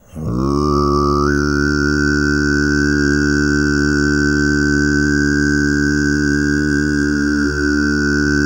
TUV3 DRONE05.wav